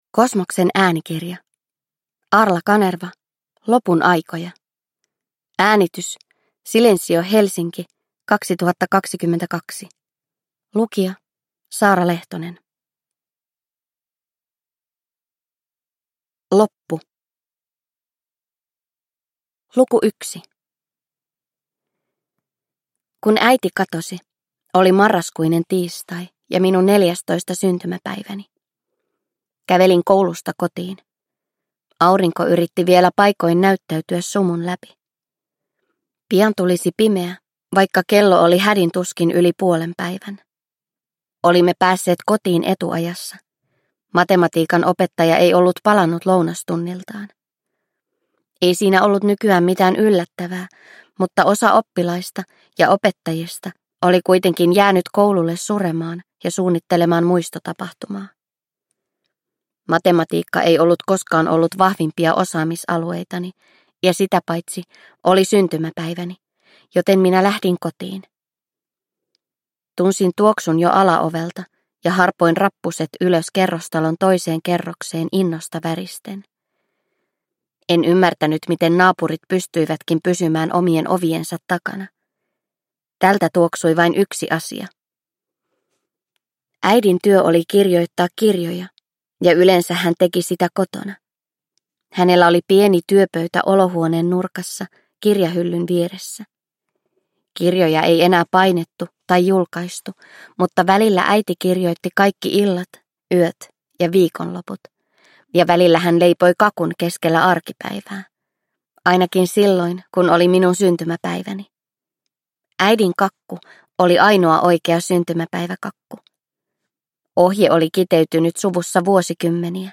Lopun aikoja – Ljudbok – Laddas ner